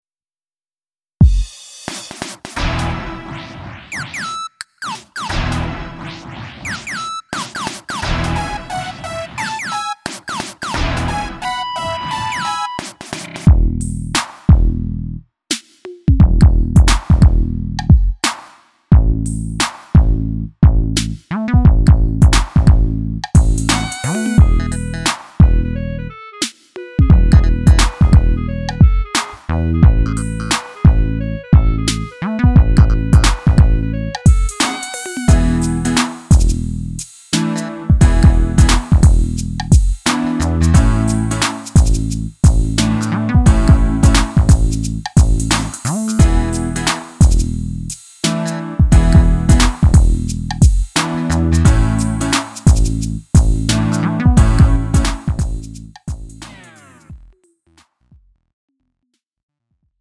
음정 -1키 2:39
장르 가요 구분 Lite MR